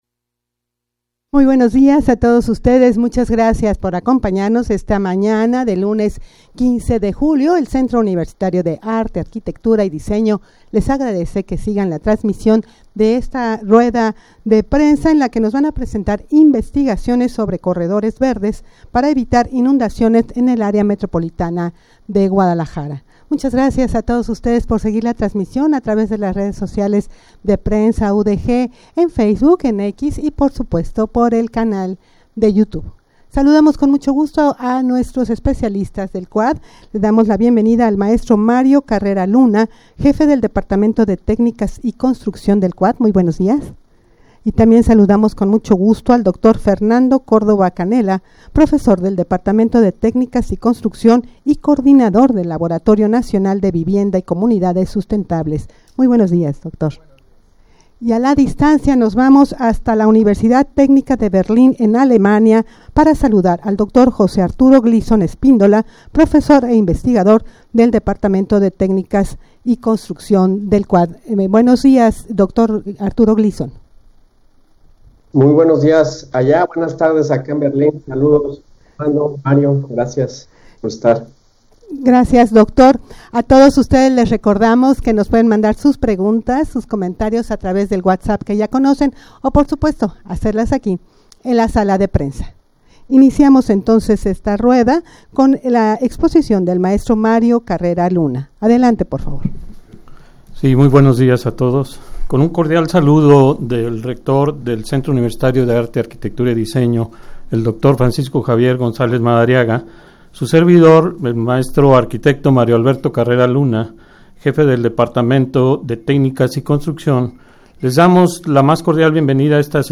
Audio de la Rueda de Prensa
rueda-de-prensa-para-presentar-investigaciones-sobre-corredores-verdes-para-evitar-inundaciones-en-el-amg_0.mp3